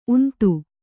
♪ untu